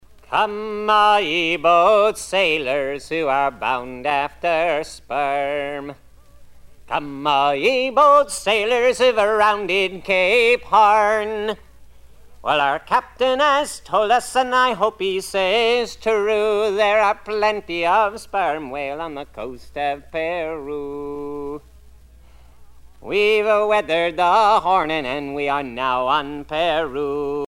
Sea chanteys and sailor songs